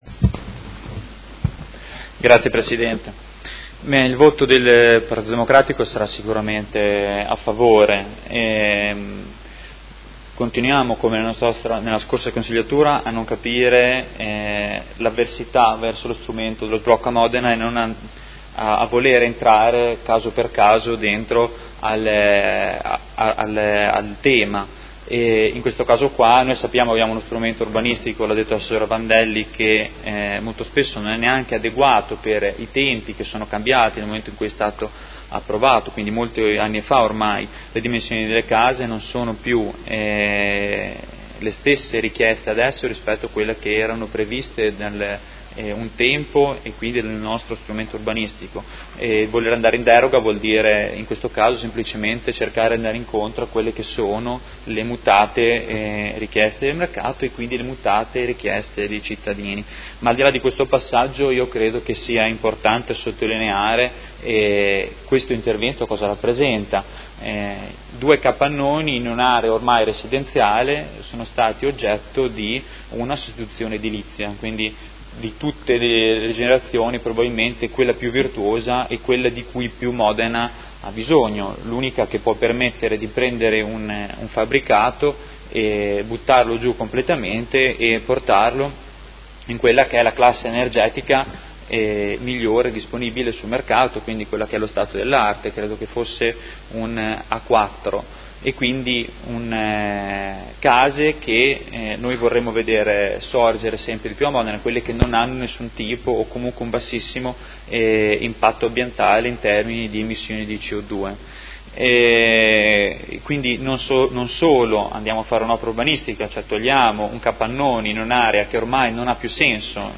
Seduta del 25/07/2019 Dichiarazione di voto.